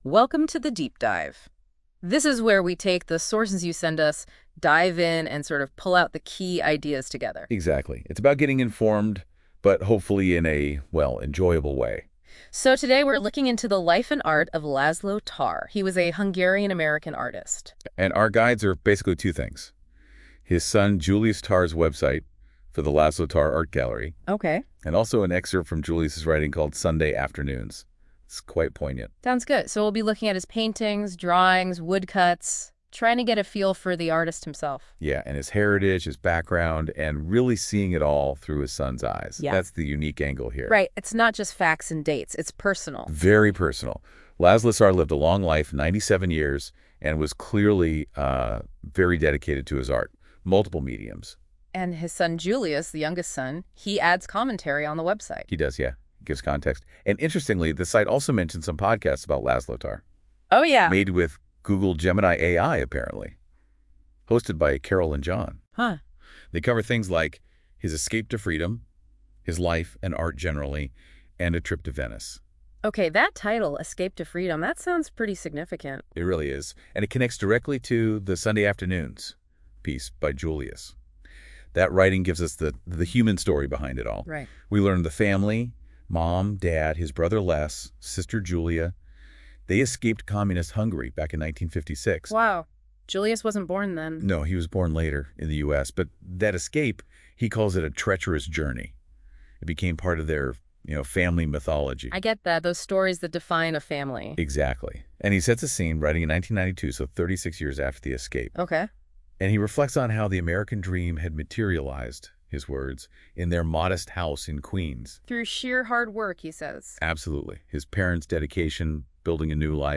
Podcasters